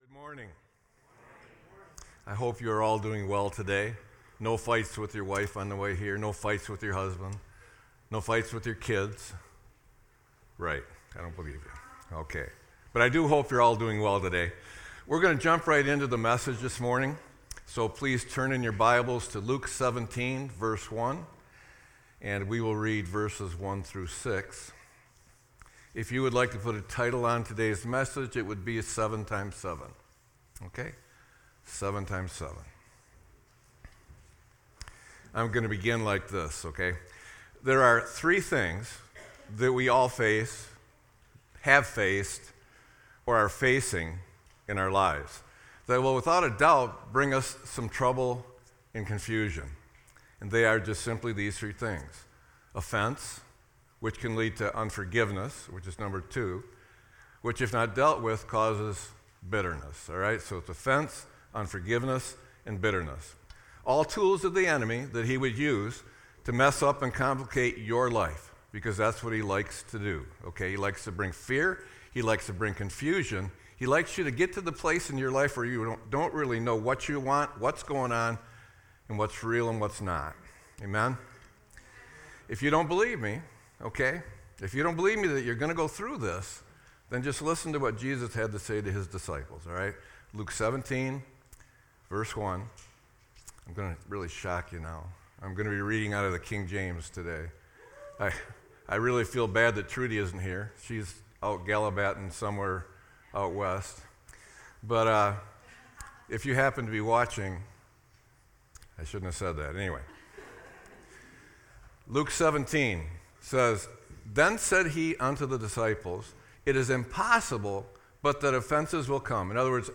Sermon-9-07-25.mp3